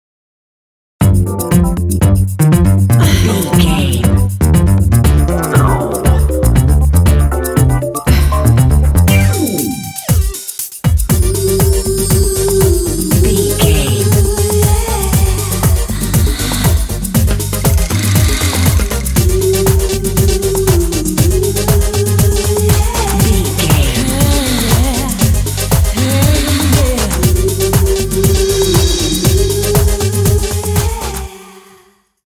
Aeolian/Minor
soothing
sultry
drums
bass guitar
synthesiser
electric organ
conga
r& b
synth pop